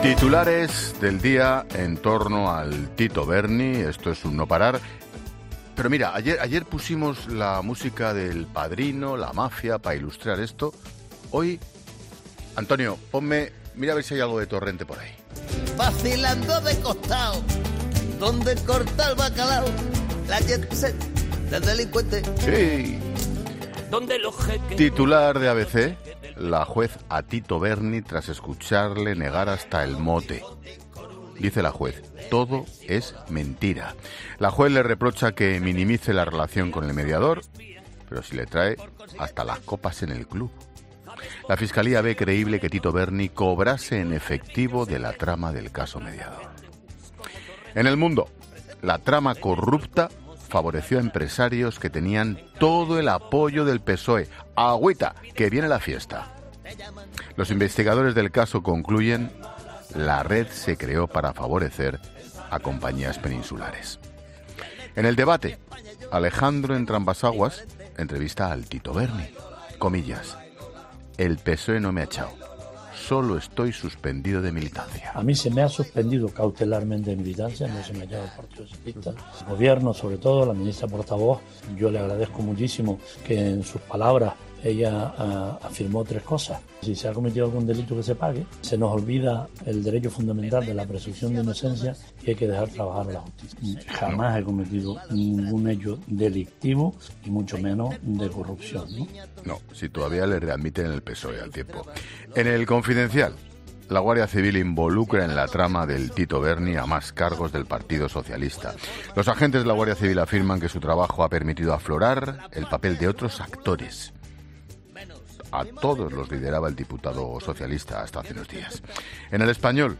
Monólogo de Expósito
El director de La Linterna analiza hasta ocho titulares clave que aportan nueva información sobre el Tito Berni y Espinosa Navas